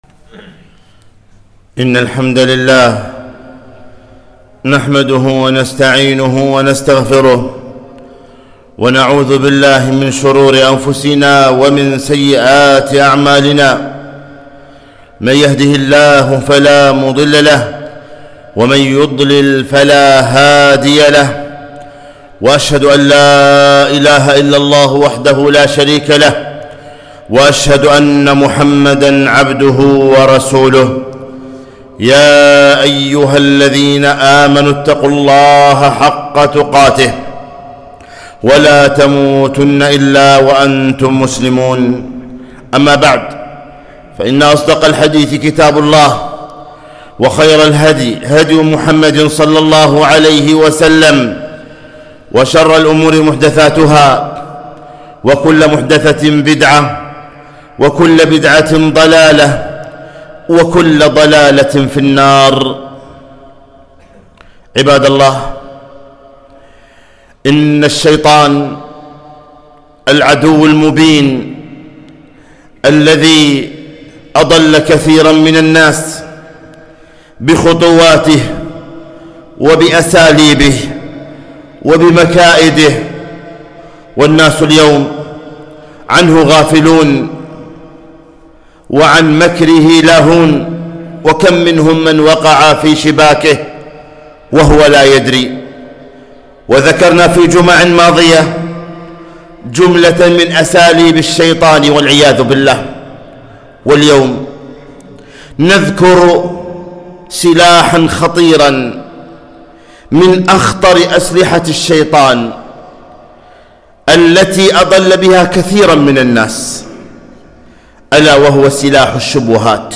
خطبة - احذروا الشبهات 7-7-1442